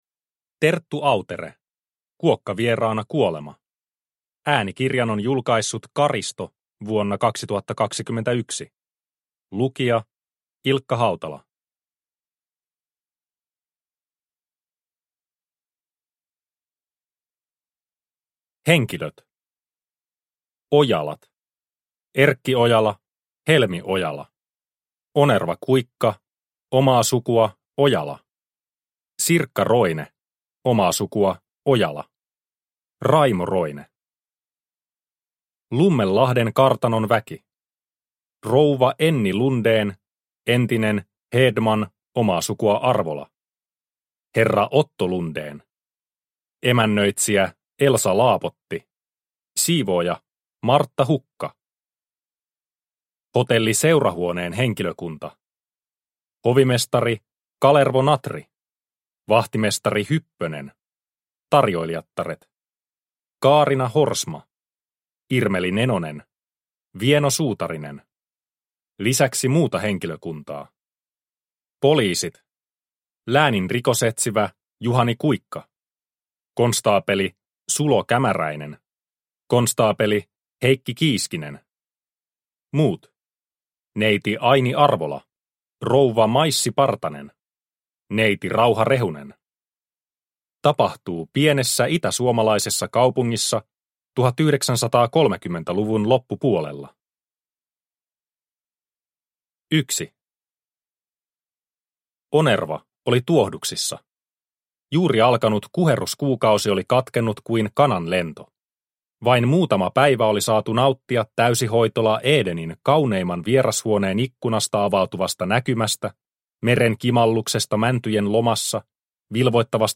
Kuokkavieraana kuolema – Ljudbok – Laddas ner